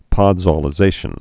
(pŏdzô-lĭ-zāshən) also pod·sol·i·za·tion (pŏdsô-)